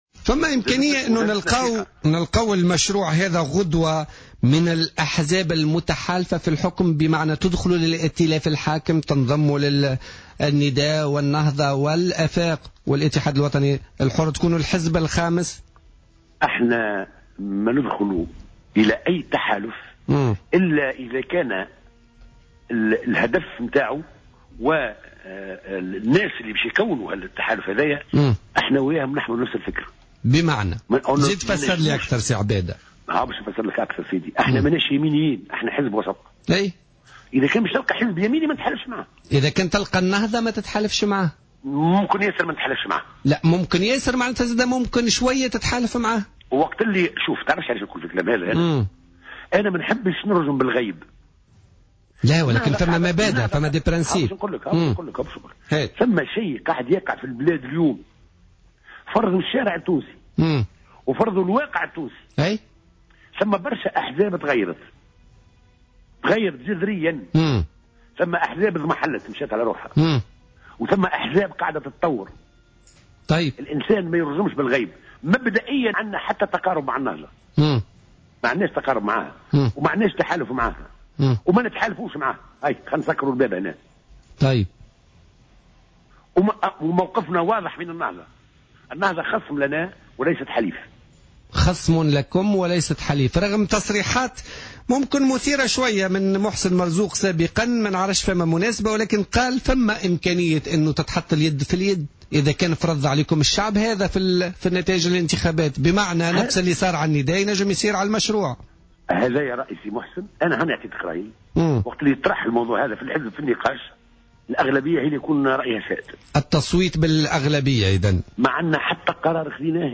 Abada Kefi, président de la commission des droits et des relations extérieures à l'ARP et l'un des fondateurs du parti Harakat Machroû Tounès était l'invité ce jeudi 17 mars 2016 de l'émission Politica sur Jawhara Fm.